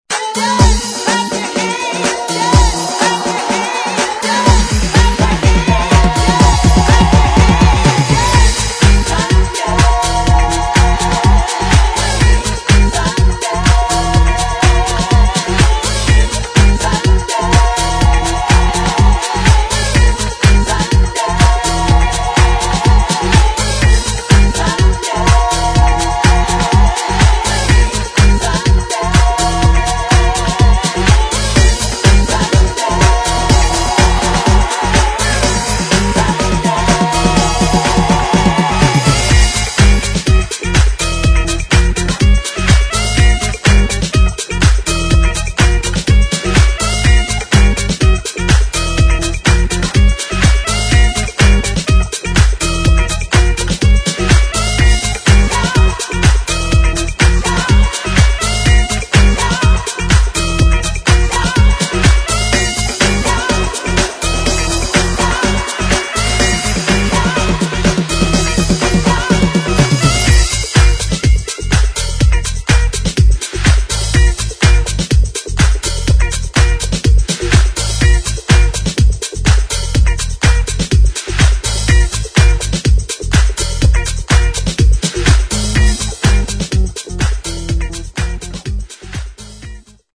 [ DISCO / HOUSE ]